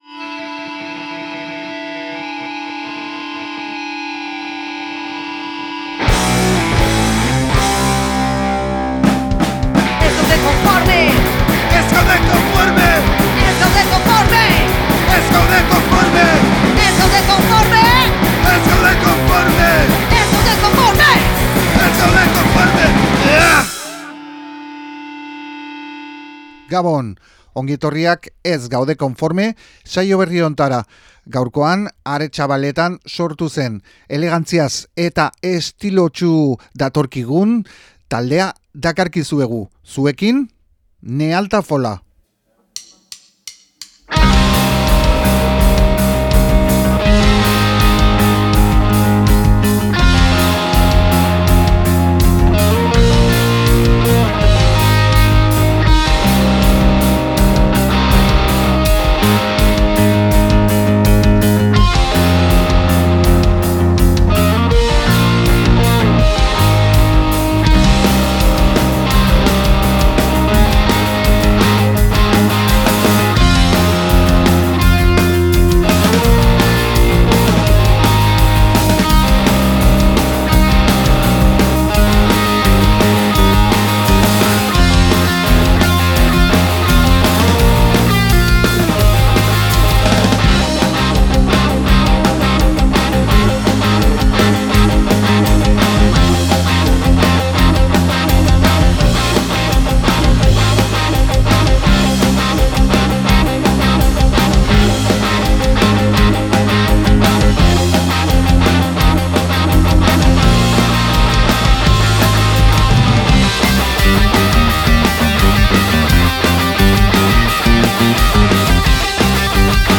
14:35 – 30:20 Elkarrizketa